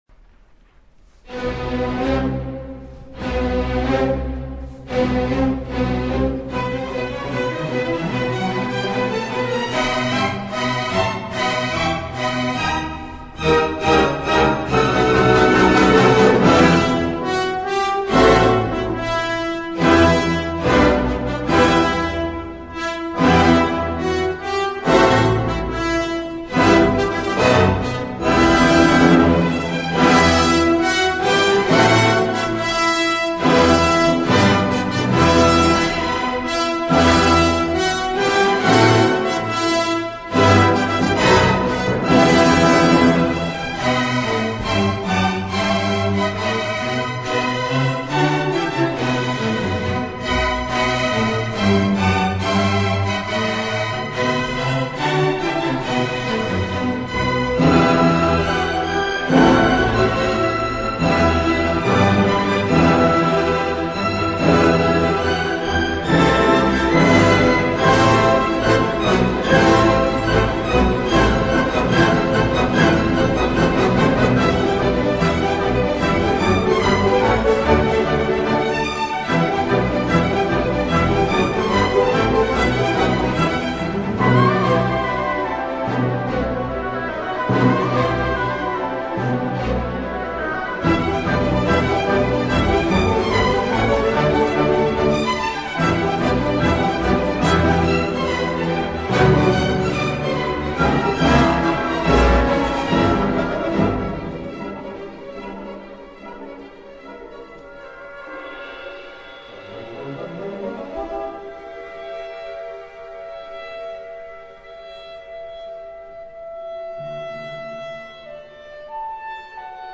Dvorak_Symphony_n9_4th_movement.mp3